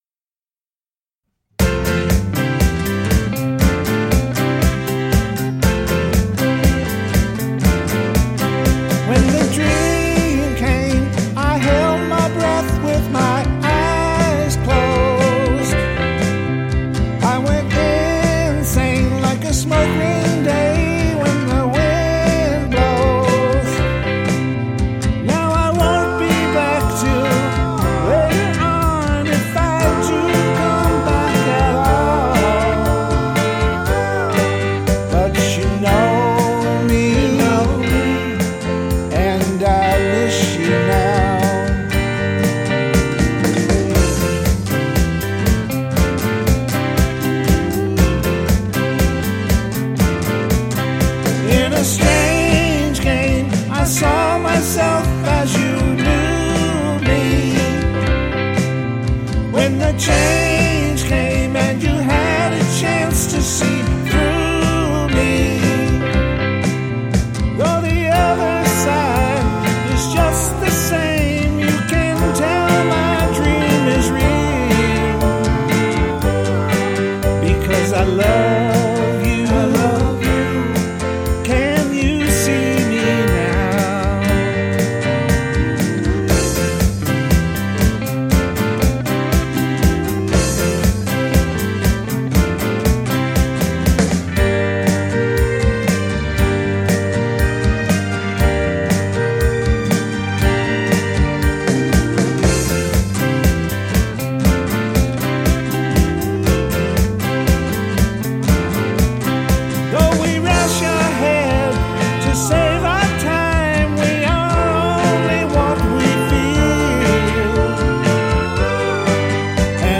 - Classic Rock Covers -
Volume 2 - Classic Country / Folk Rock